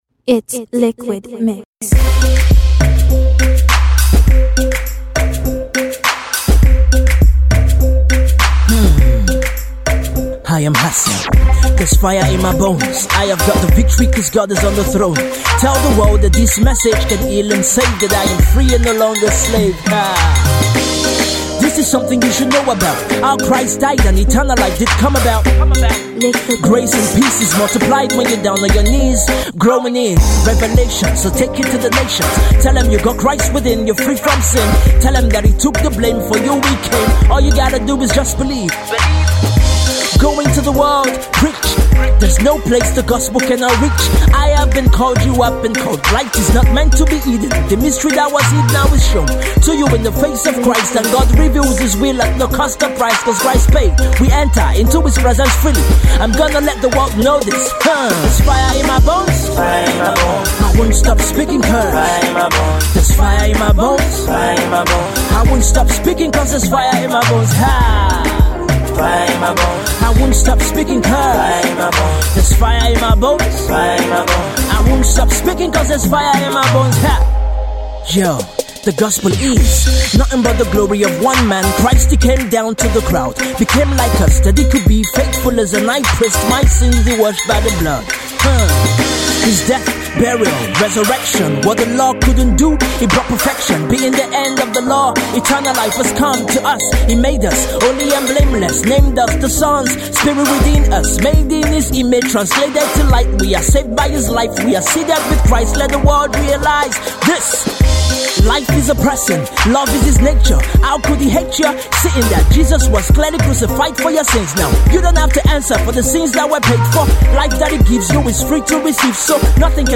sizzling rap mix
gospel rapper